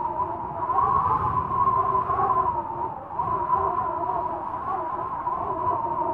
whistling_wind1.ogg